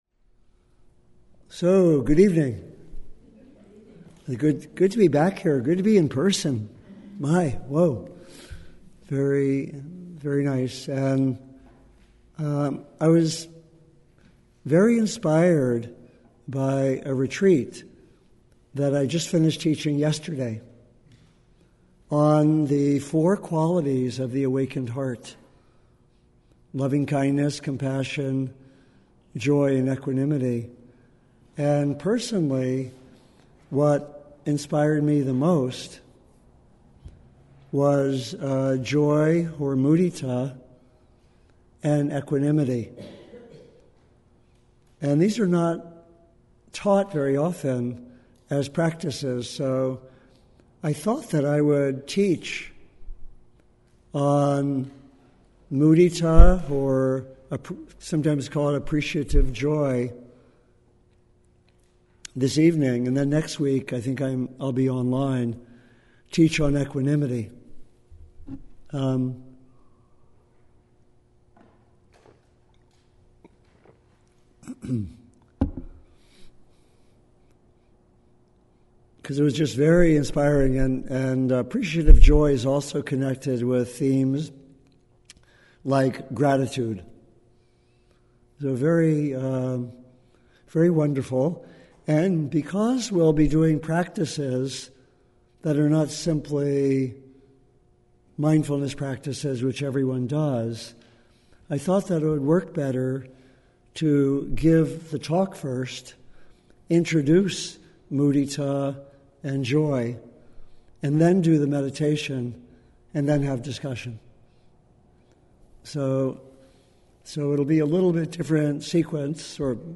Cultivating Joy and Developing Mudita (Appreciative Joy) (Dharma talk followed by guided sits)